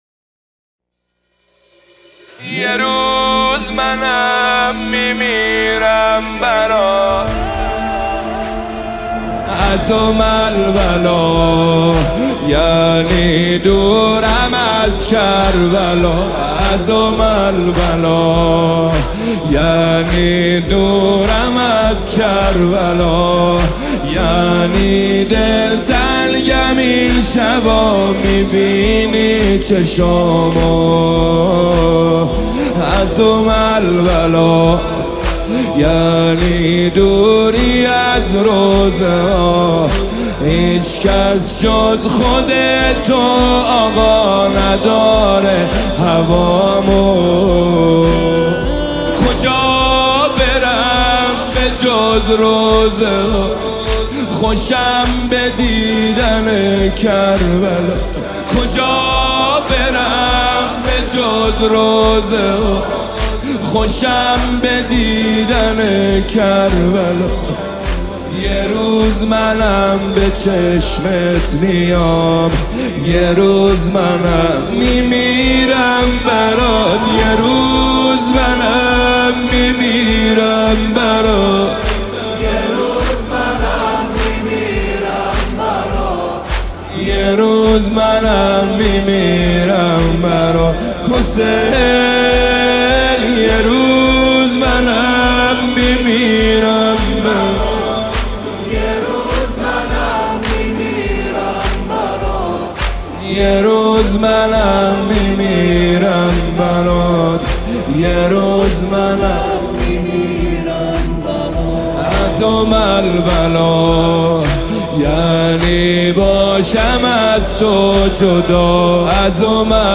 مداحی ماه محرم